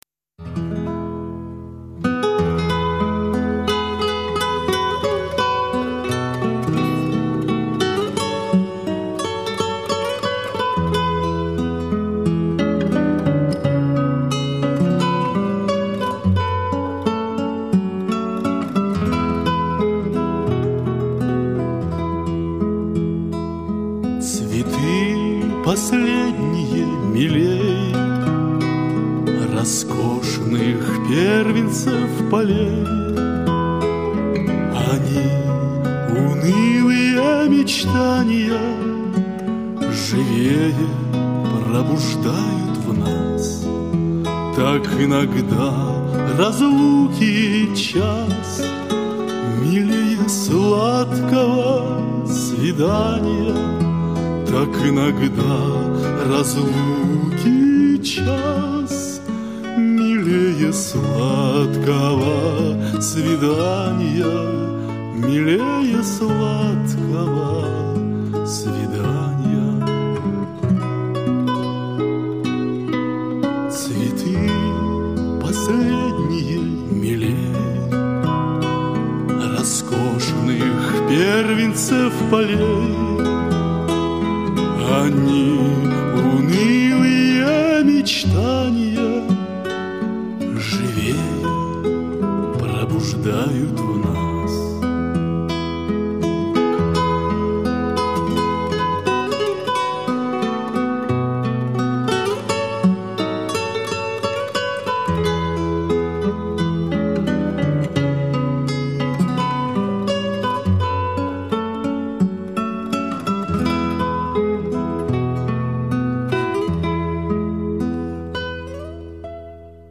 Вы услышите старинные русские романсы, а также романсы, написанные самим исполнителем на стихи поэтов золотого и серебряного века.
гитара, вокал